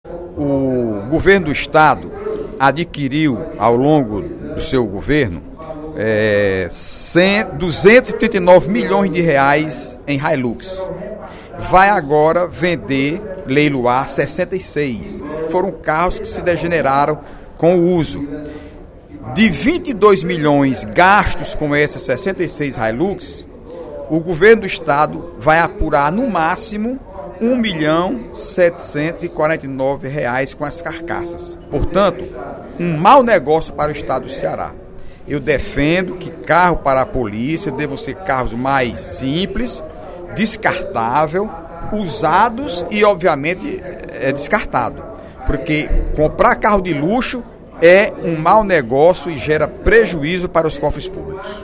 O deputado Heitor Férrer (PDT) questionou, durante o primeiro expediente da sessão plenária da Assembleia Legislativa desta quarta-feira (25/06), os valores envolvendo as viaturas do programa Ronda do Quarteirão.